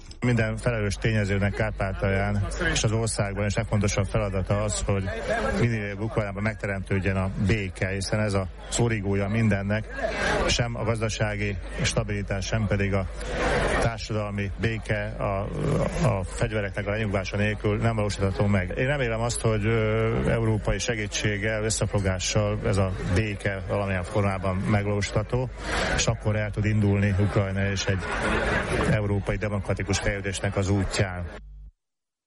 Az ukrajnai válság témaköréről is szó esett ma a 26. Bálványosi Nyári Szabadegyetemen a Háború és béke között – a hidegháború peremén című beszélgetés keretében.